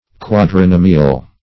Search Result for " quadrinomial" : The Collaborative International Dictionary of English v.0.48: Quadrinomial \Quad`ri*no"mi*al\, n. [Quadri- + nomial, as in binomial: cf. F. quadrin[^o]me.]
quadrinomial.mp3